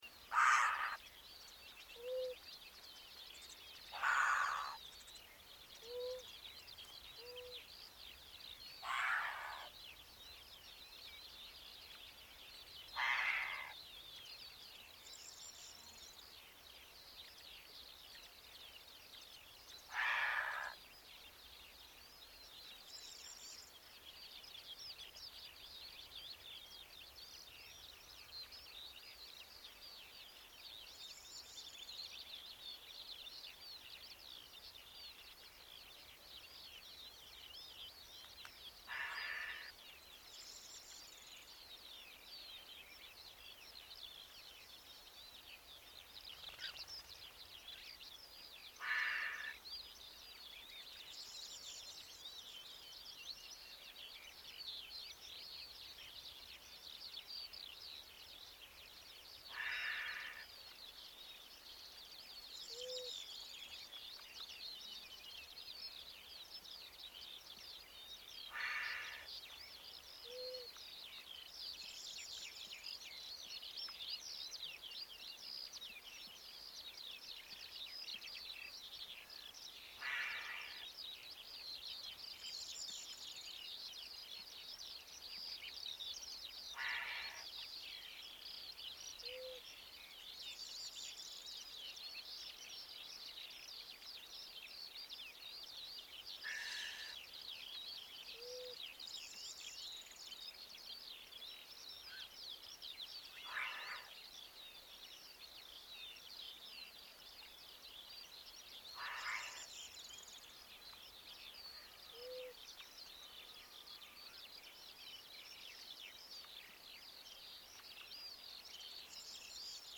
Canto